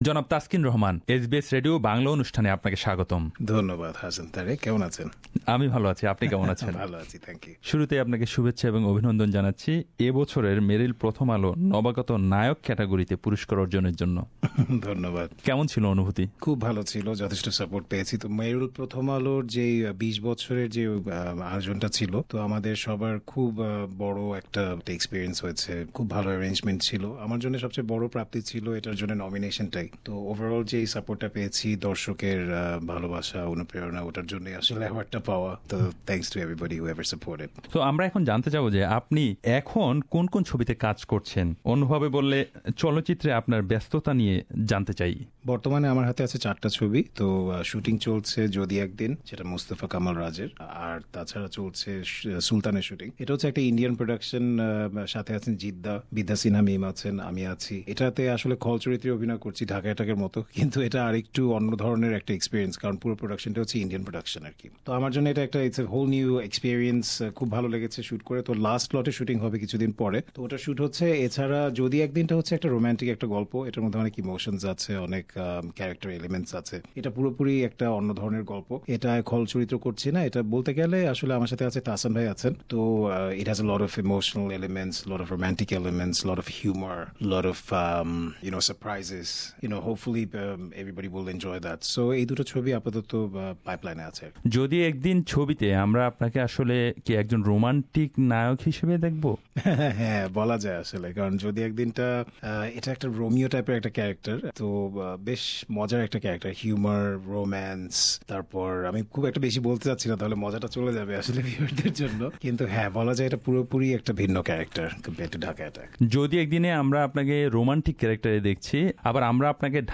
Dhallywood glamorous villain Taskeen Rahman has been busy in Dhaka and Kolkata finishing shooting feature films 'Jodi Ekdin' and 'Sultan'. SBS Bangla spoke with the actor about his career and personal life.